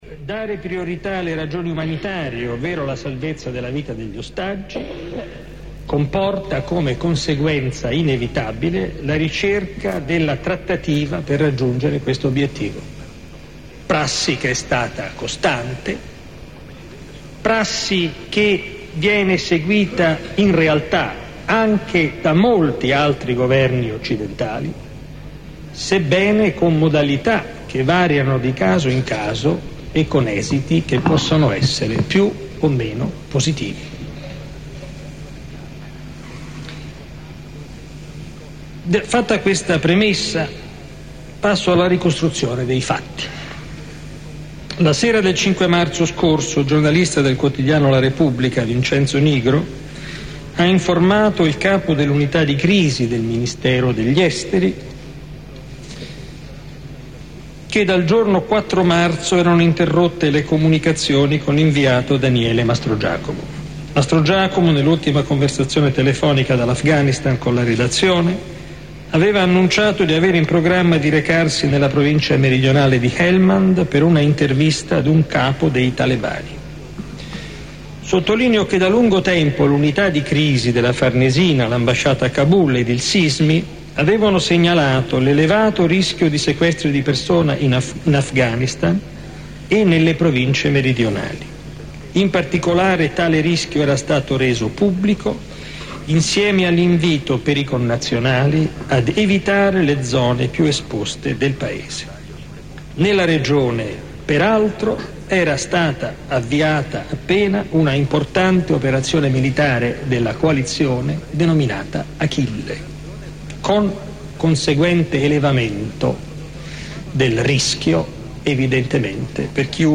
Il discorso alla Camera del ministro degli Esteri: